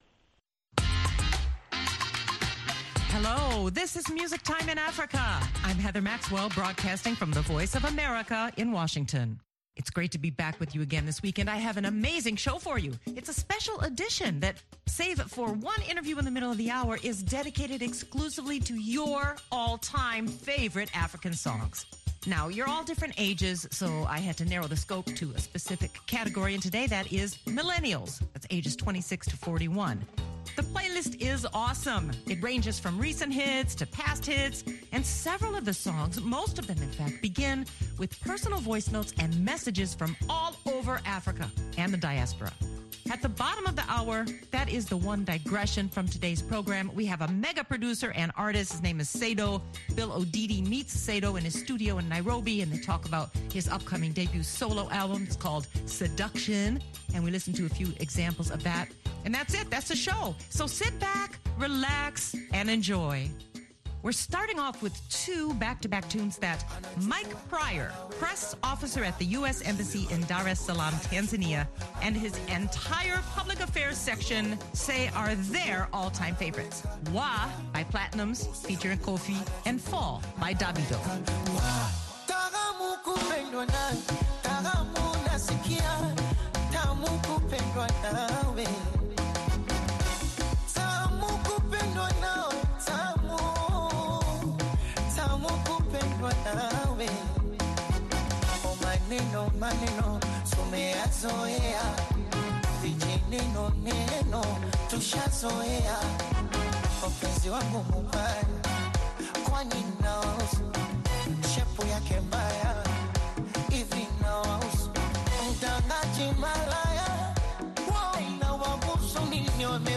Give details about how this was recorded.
And then it’s back to more African millennial hits that will groove and sway you to the end of the hour.